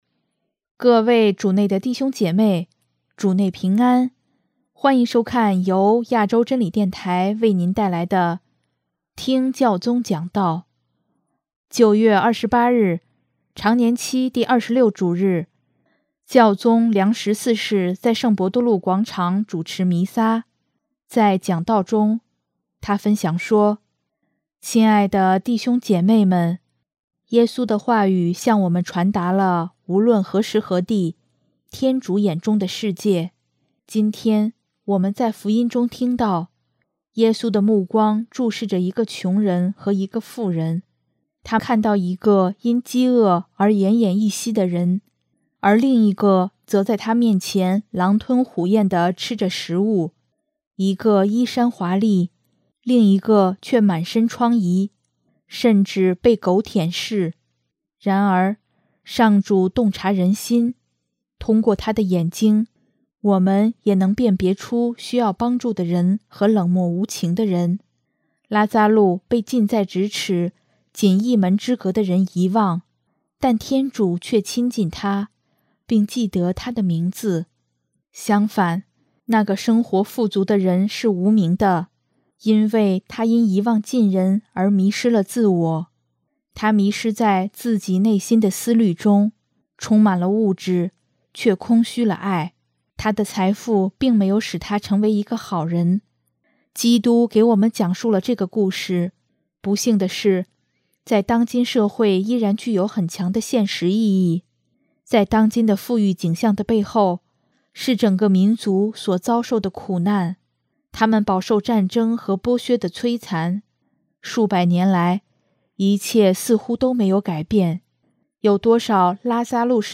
【听教宗讲道】|当今众多的“拉匝禄”提醒着我们耶稣的话语
9月28日，常年期第二十六主日，教宗良十四世在圣伯多禄广场主持弥撒，在讲道中，他分享说：